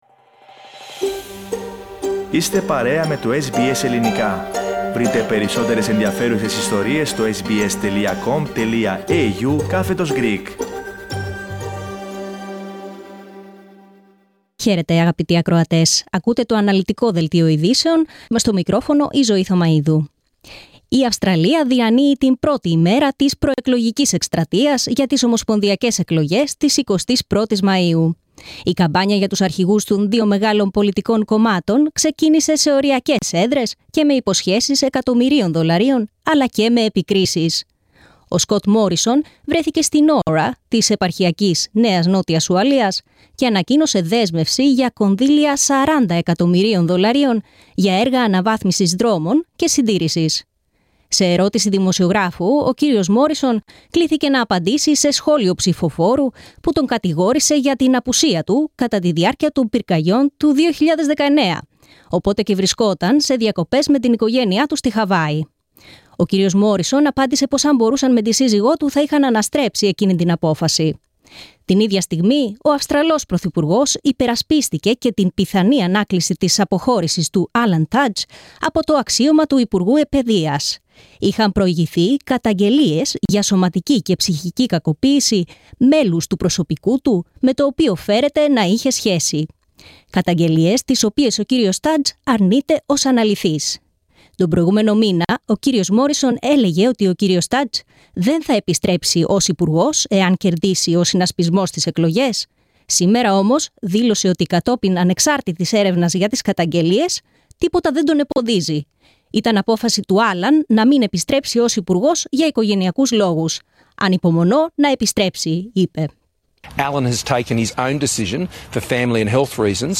Δελτίο ειδήσεων, Τρίτη 12.04.22
News in Greek. Source: SBS Radio